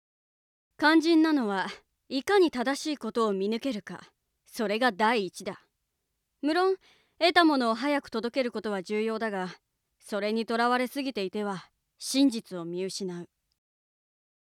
【サンプルセリフ】
（諜報の心得を諭す）